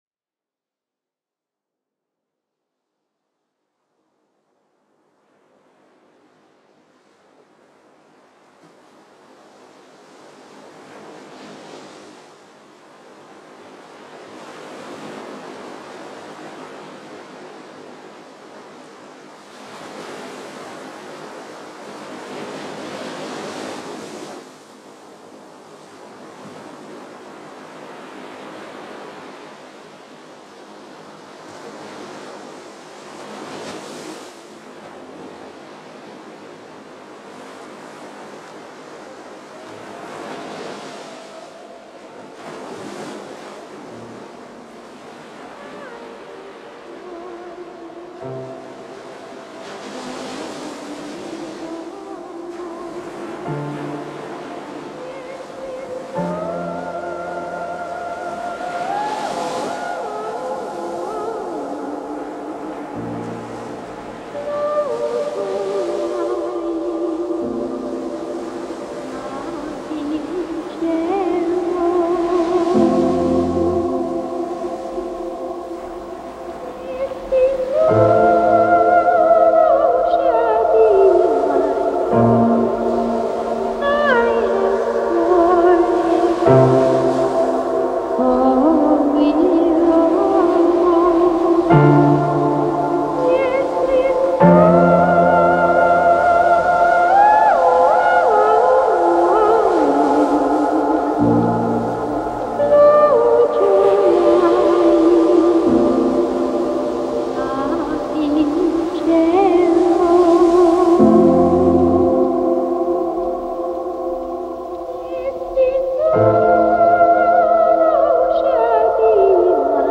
The operatic sense of familiarity
has a way with atmosphere